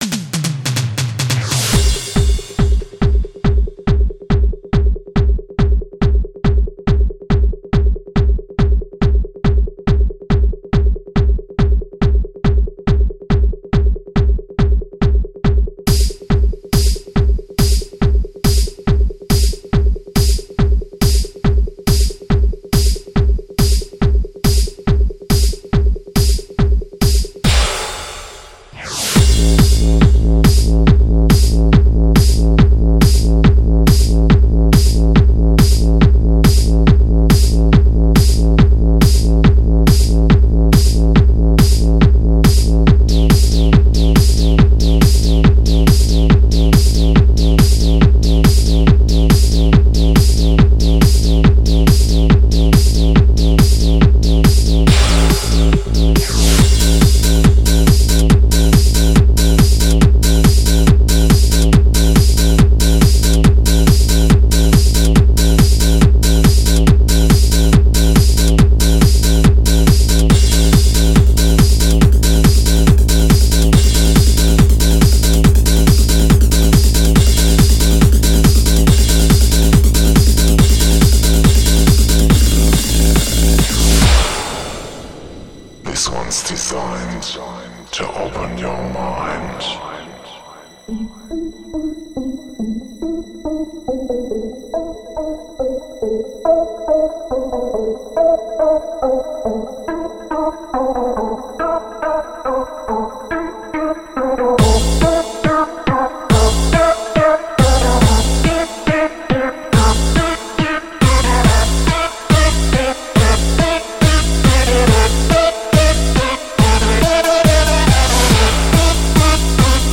Жанр: House